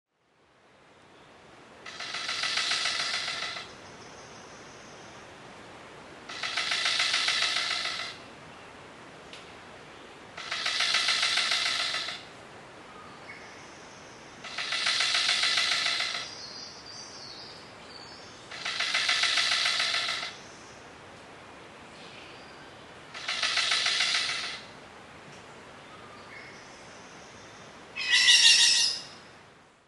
6. Golden Bowerbird
What is special about me? I am bright yellow and I can imitate the calls of other animals. For instance, I can croak like a frog
GoldenBowerbird.mp3